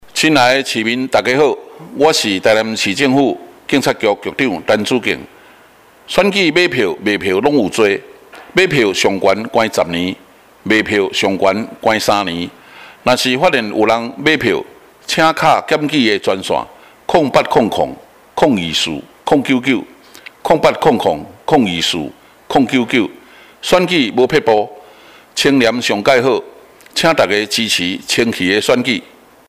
「第九屆立法委員暨第十四任總統副總統選舉」反賄選廣播
臺南地檢署檢察長張文政(國語).mp3 (另開新視窗)